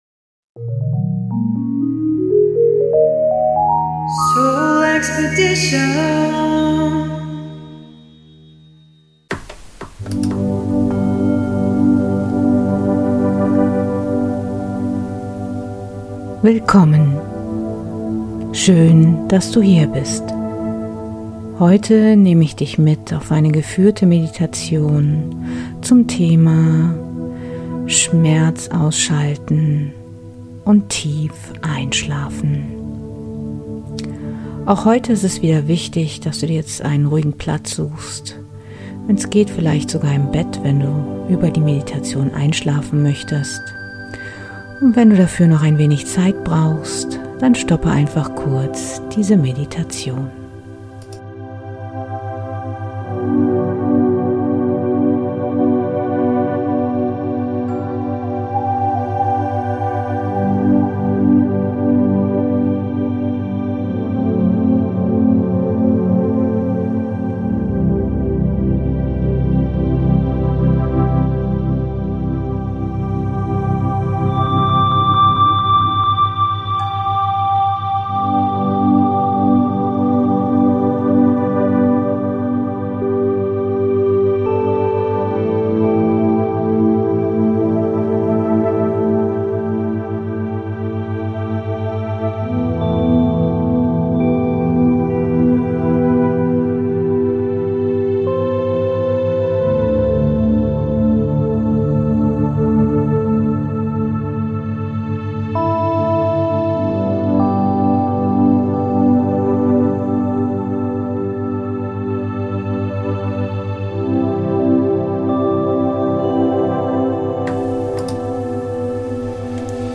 Meditation/Hypnose Schmerzausschaltung im Schlaf ~ SoulExpedition Podcast
Beschreibung vor 2 Jahren Bitte benutze Kopfhörer für einen optimalen Hörgenuss!